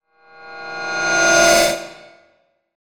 Horror Sliding Sound Effect Free Download
Horror Sliding